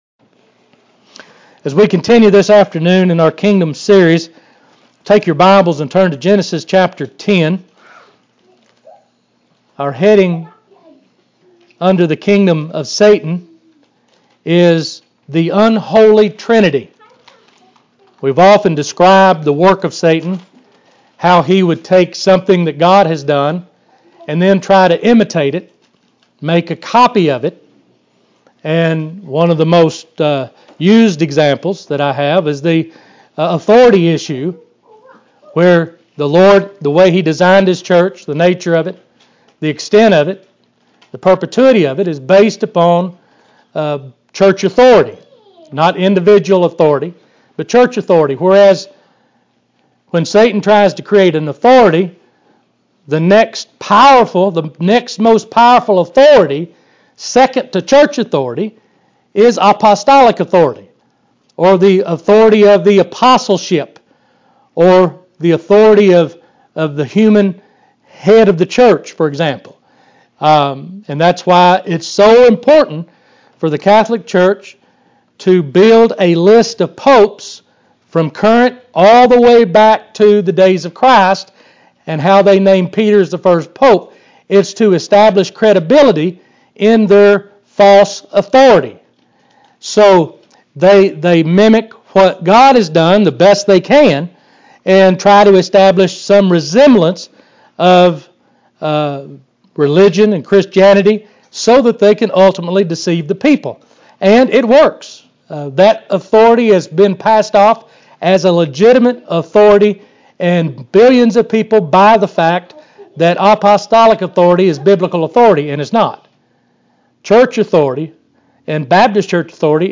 Below you will find the notes (pdf) to follow along with each audio lecture.